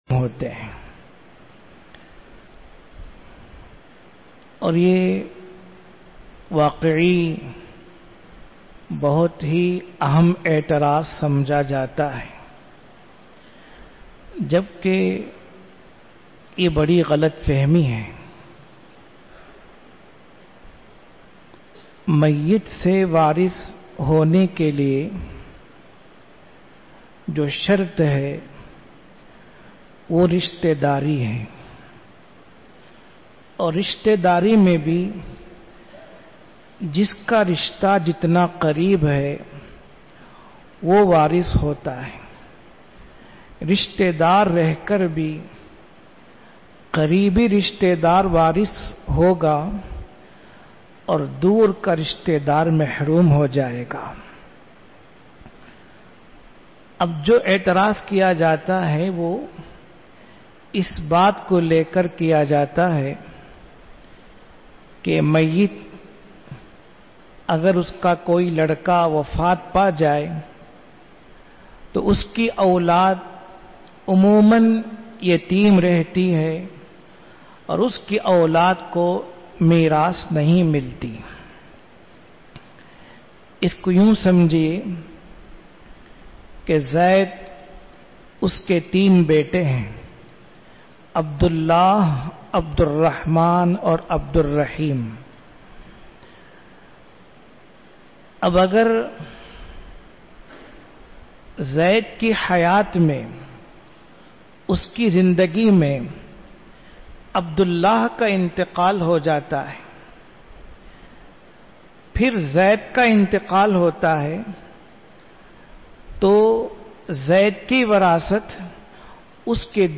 Urdu Bayans Majlis-e-Jamiulkhair, Jamiya Mosque, Ambur.